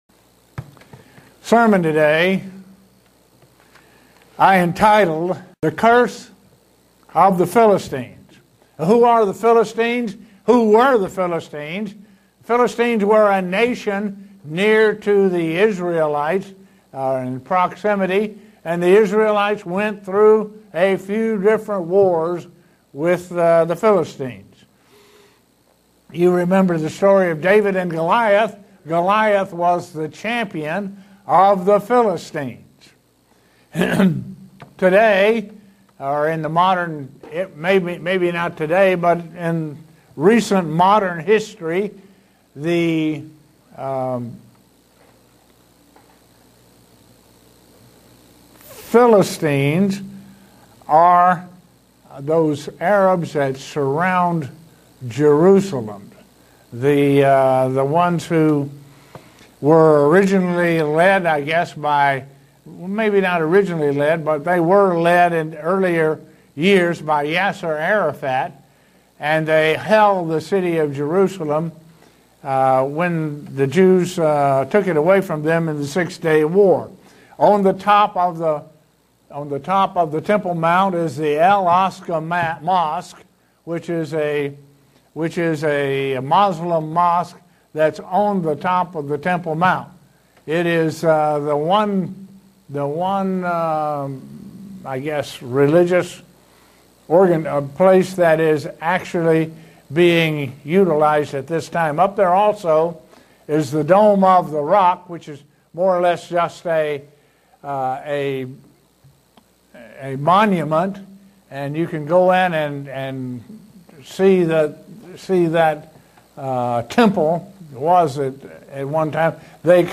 Given in Buffalo, NY
Print How this curse existance today where it can be seen and its form. sermon Studying the bible?